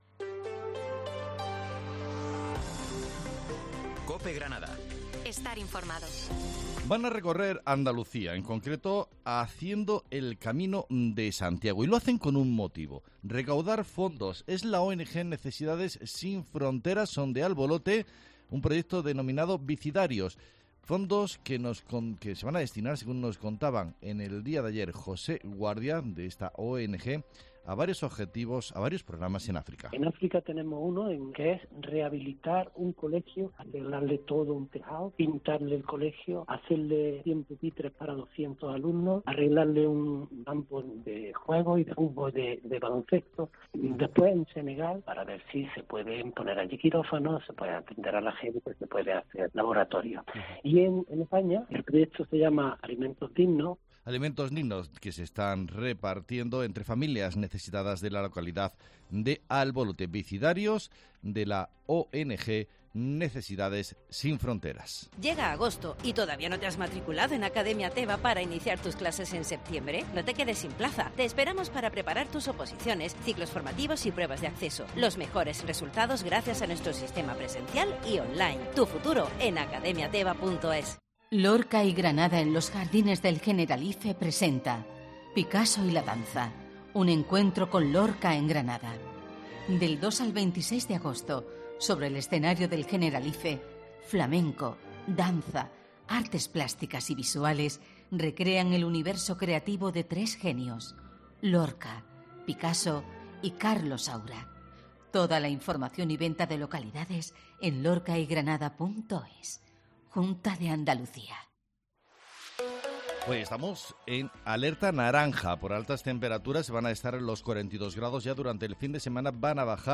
Herrera en Cope Granada, Informativo del 11 de agosto